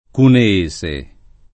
vai all'elenco alfabetico delle voci ingrandisci il carattere 100% rimpicciolisci il carattere stampa invia tramite posta elettronica codividi su Facebook cuneese [ kune- %S e ] (lett. cuneense [ kune- $ n S e ]) etn.